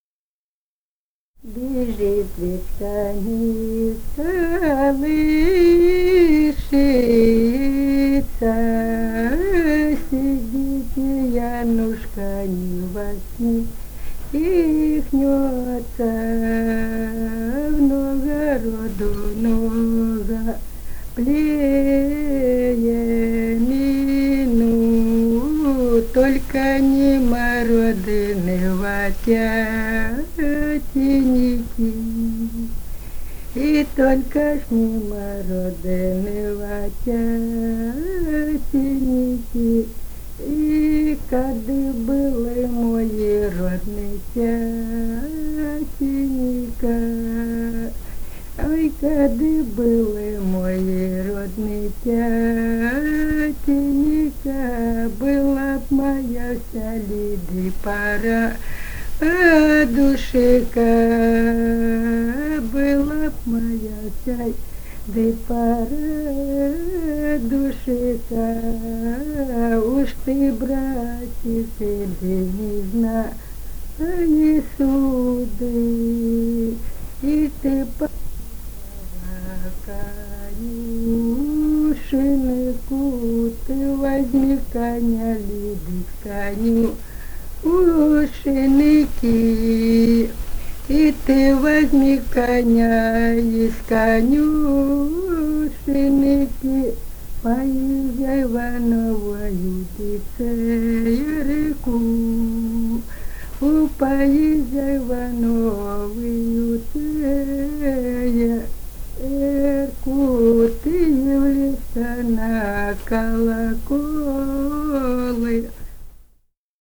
полевые материалы
Румыния, с. Переправа, 1967 г. И0973-09